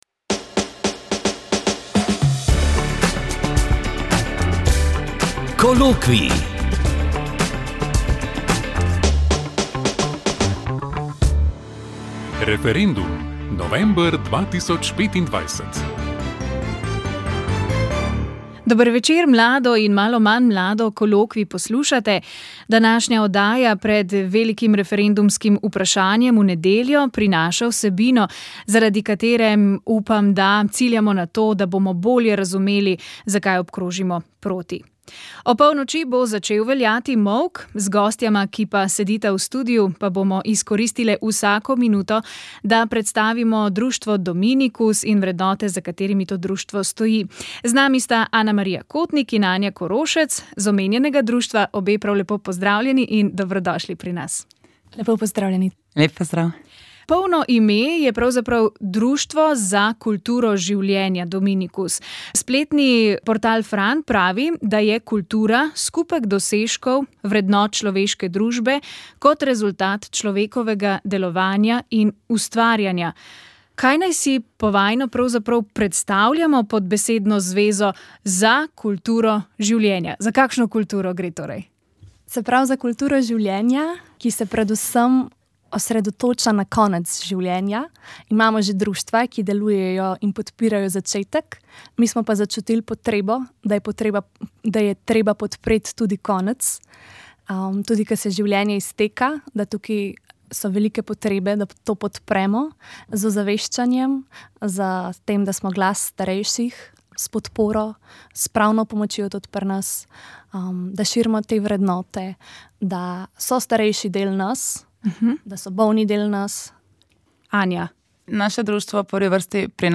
Kolokvij na veliki petek prinaša predvsem razmislek - ob odlomkih iz našega radijskega misijona Cerkev je mlada ter ob primerni glasbi.